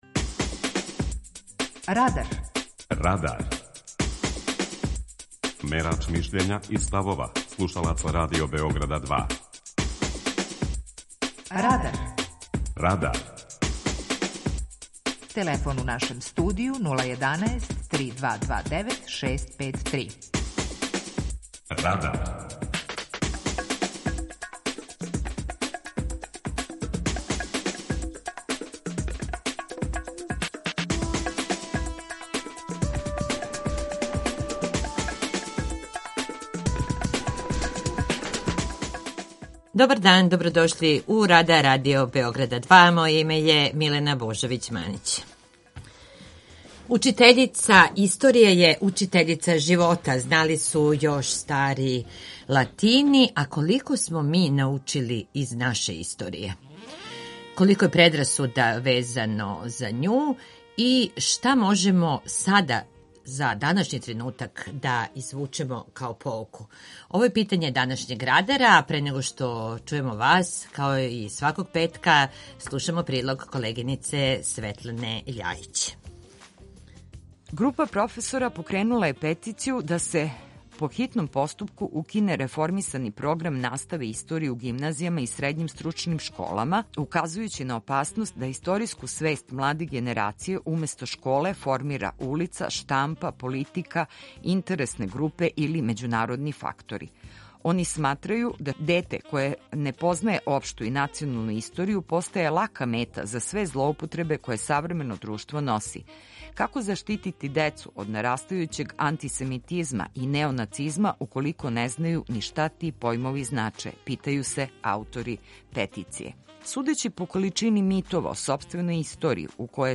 Питање Радара је: Kолико учимо из историје? преузми : 18.96 MB Радар Autor: Група аутора У емисији „Радар", гости и слушаоци разговарају о актуелним темама из друштвеног и културног живота.